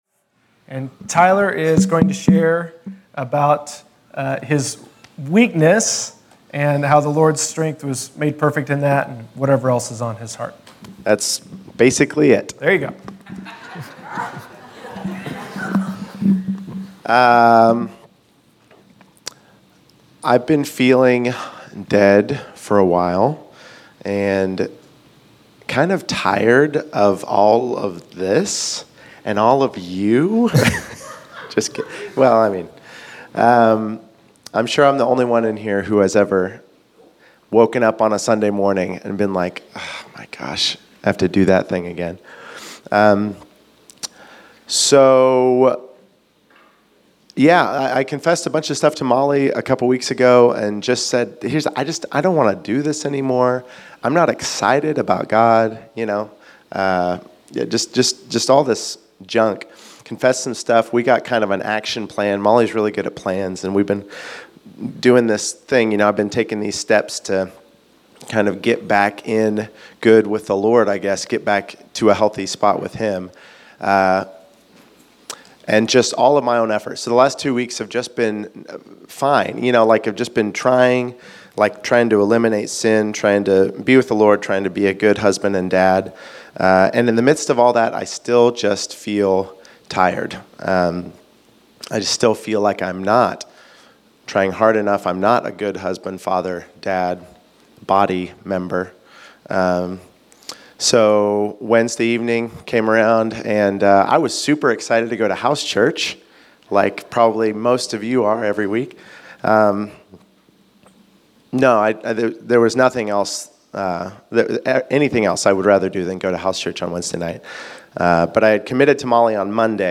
Category: Testimonies